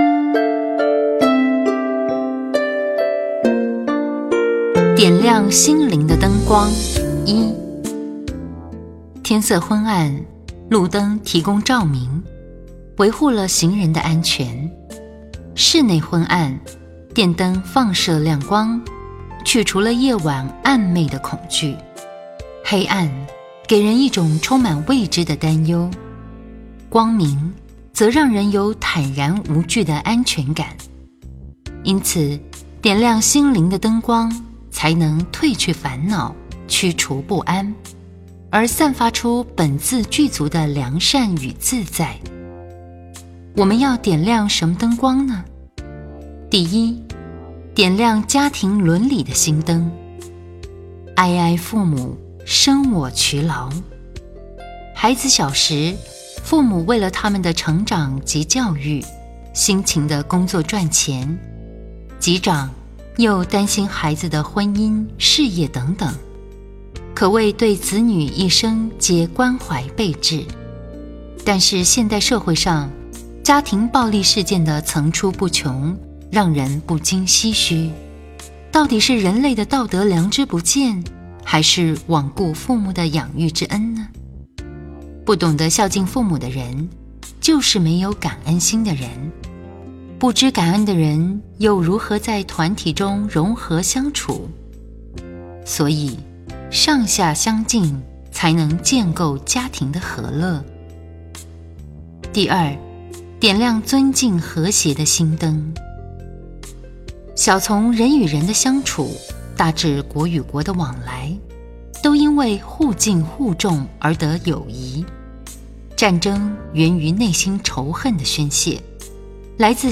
68.点亮心灵的灯光(一)--佚名 冥想 68.点亮心灵的灯光(一)--佚名 点我： 标签: 佛音 冥想 佛教音乐 返回列表 上一篇： 64.平常心是道--佚名 下一篇： 69.点亮心灵的灯光(二)--佚名 相关文章 24.